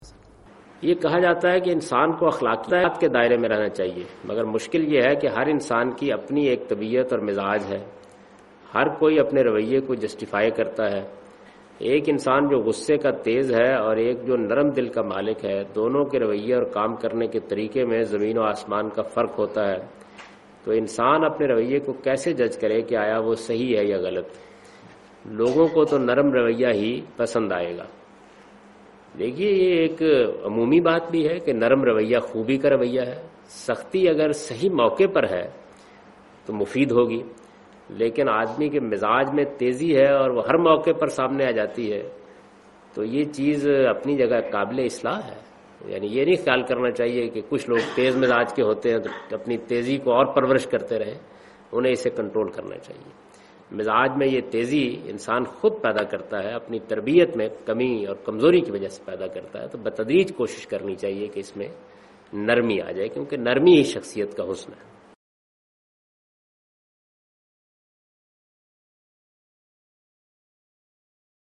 Javed Ahmad Ghamidi responds to the question 'Characterisitics and limit of moral aptitude'?
جاوید احمد غامدی "نمایاں صفت اور اخلاقی حدود کی استعداد" کے متعلق سوال کا جواب دے رہے ہیں